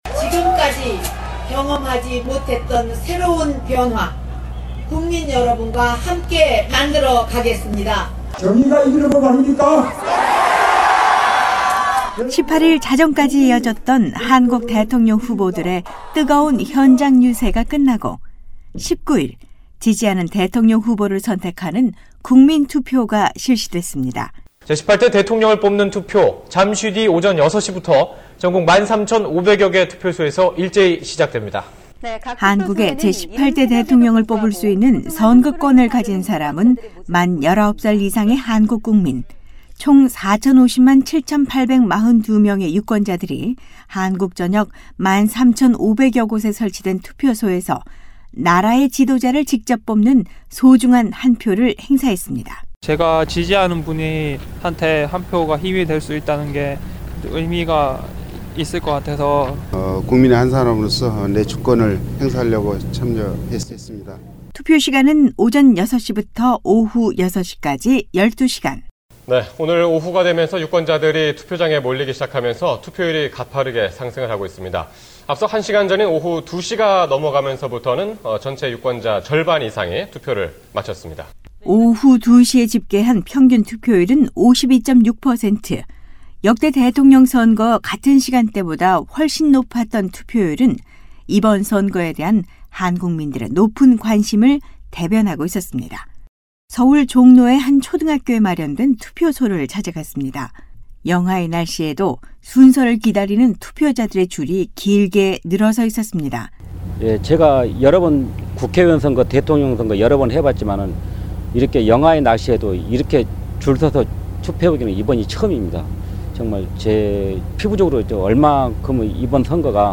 대통령을 뽑는 한국 사람들의 다양한 목소리!